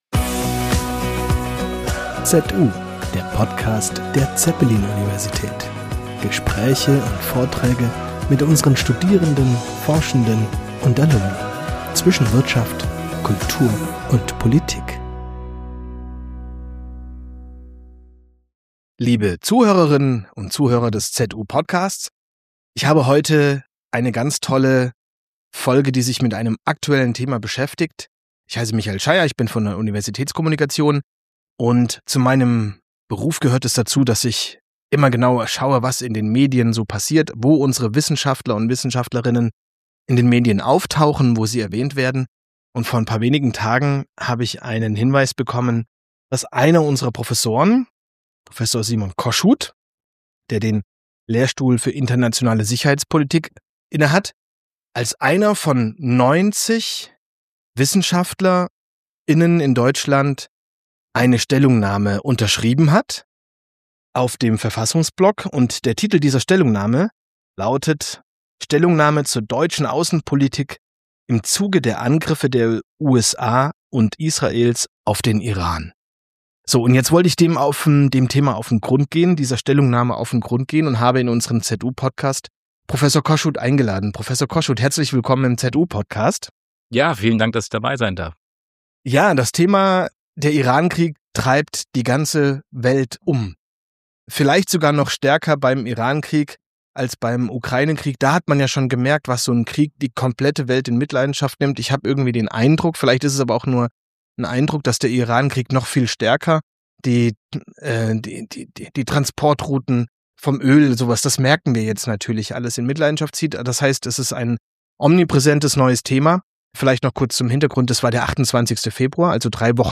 Der Anlass für unser Gespräch ist eine bedeutende Stellungnahme von 90 Wissenschaftlerinnen und Wissenschaftlern, die sich kritisch mit der deutschen Außenpolitik im Kontext der militärischen Angriffe der USA und Israels auf den Iran auseinandersetzt.